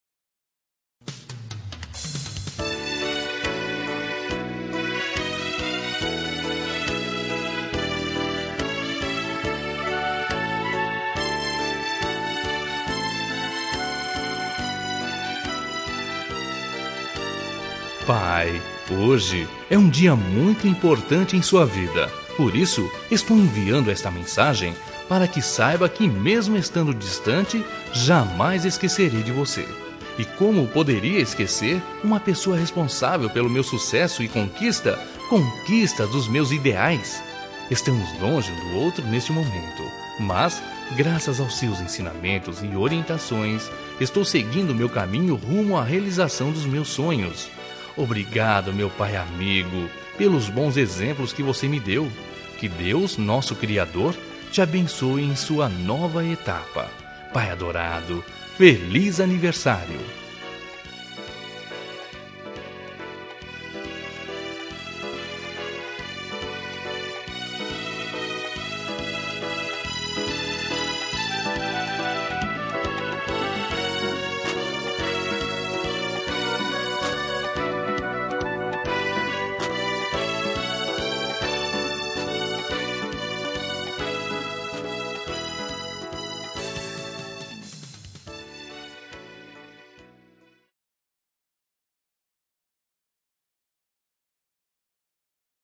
Telemensagem de Aniversário de Pai – Voz Masculina – Cód: 1511 Distante Linda